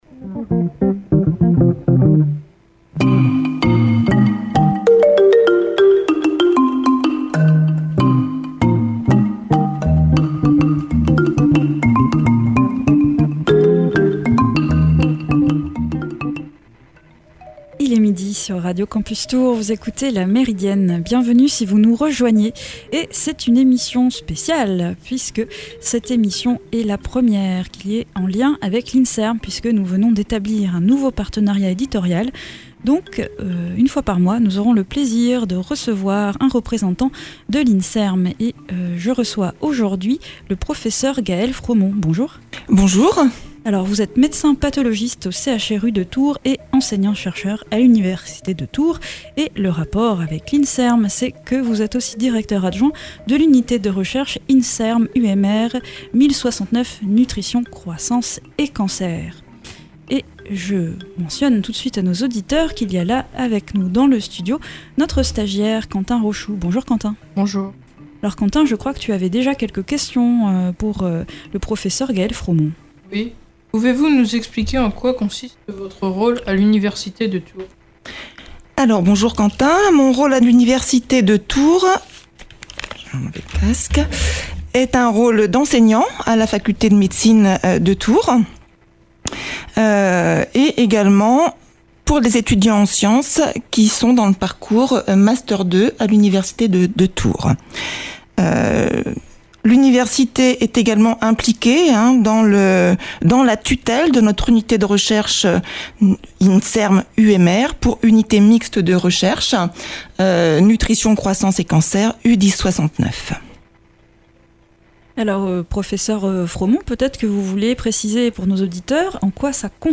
Radio Campus Tours - 99.5 FM